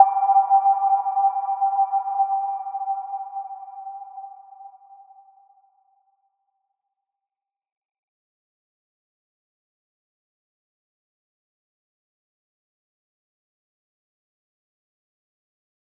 Little-Pluck-G5-f.wav